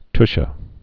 (tshə)